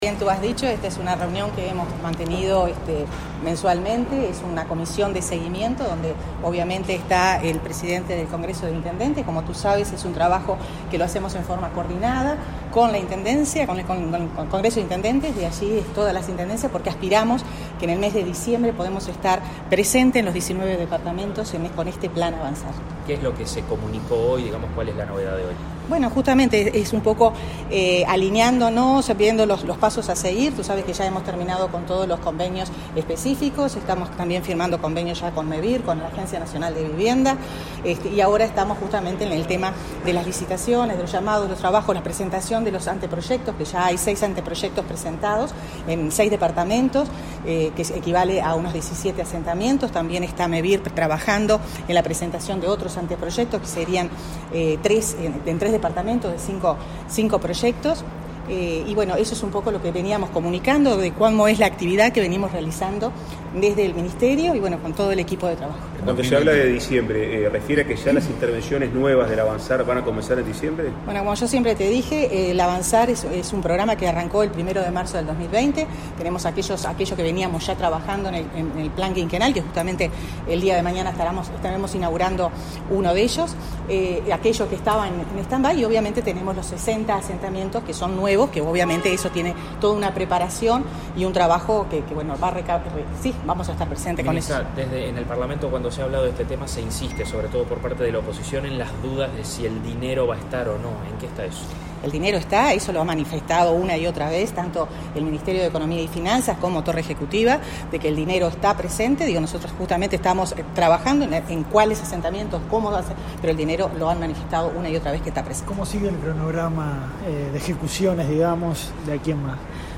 Declaraciones de la ministra de Vivienda y el presidente del Congreso de Intendentes
El martes 30, la ministra de Vivienda, Irene Moreira, y el presidente del Congreso de Intendentes, Guillermo López, dialogaron con la prensa luego de